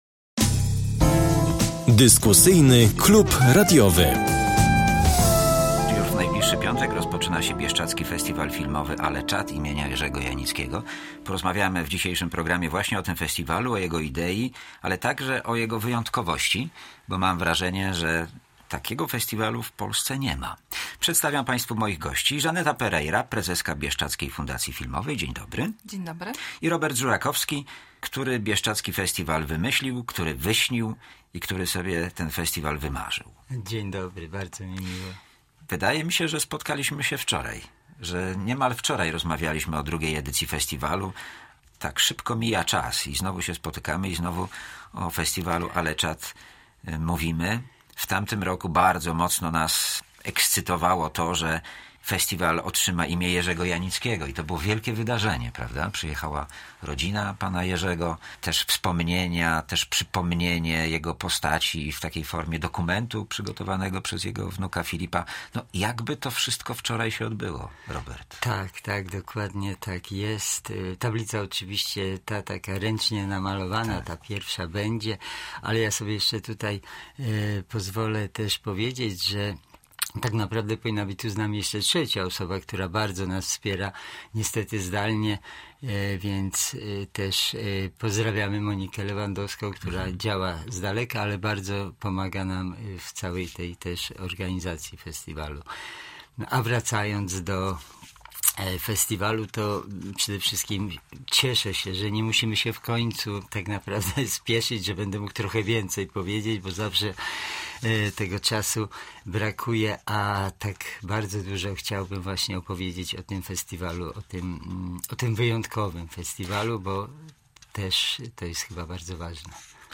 O idei festiwalu i jego tegorocznym programie rozmawiają